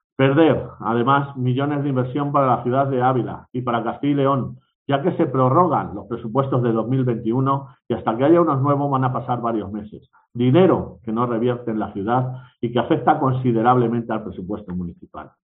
Carlos López, portavoz de Ciudadanos. Pleno presupuestos 2022